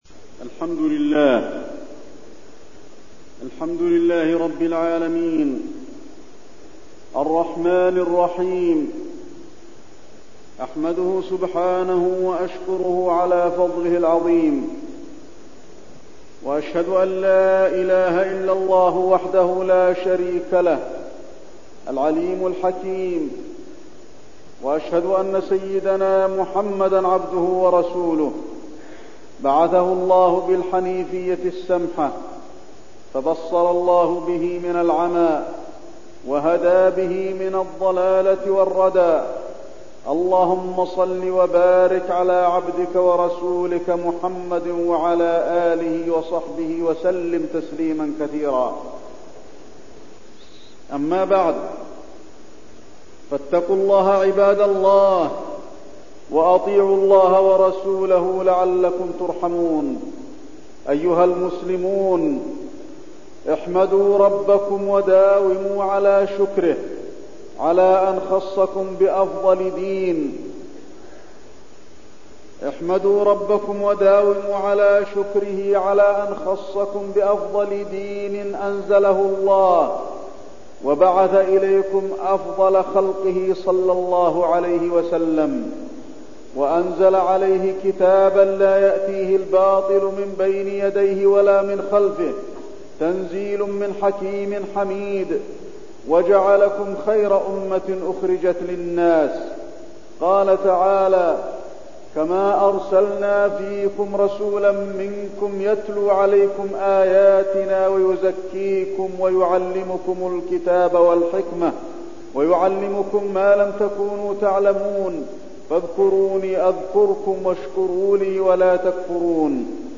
تاريخ النشر ٦ ذو الحجة ١٤٠٧ هـ المكان: المسجد النبوي الشيخ: فضيلة الشيخ د. علي بن عبدالرحمن الحذيفي فضيلة الشيخ د. علي بن عبدالرحمن الحذيفي أركان الإسلام وعقوق الوالدين